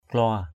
/klʊa/ 1.